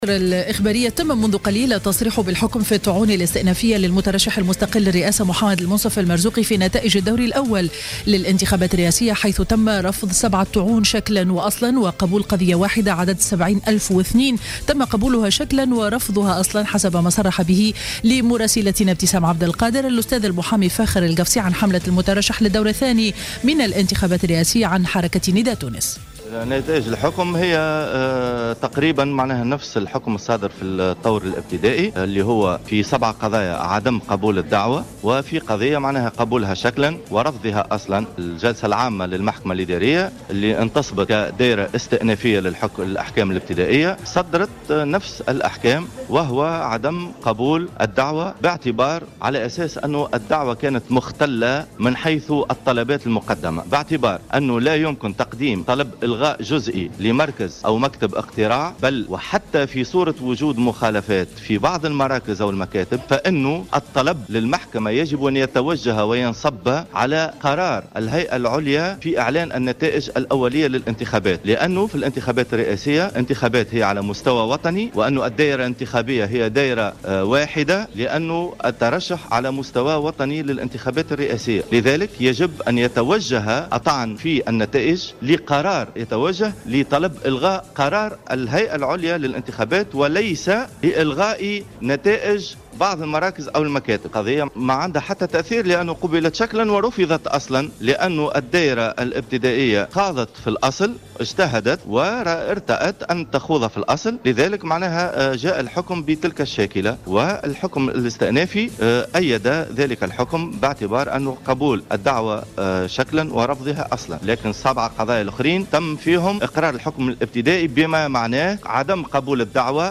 نشرة أخبار منتصف النهار ليوم الأحد 07-12-14